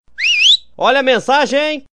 Toque Olha a Mensagem!
Toque de Chegou Mensagem Pra Você Áudio E quem disse que isso é problema meu
Categoria: Toques
Descrição: Baixar toque Olha a Mensagem! em mp3, download sons de notificação Fiu fiu Olha a mensagem... mp3 grátis para WhatsApp, Android, Samsung, Vivo... iPhone.
toque-de-notificacao-olha-a-mensagem-pt-www_tiengdong_com.mp3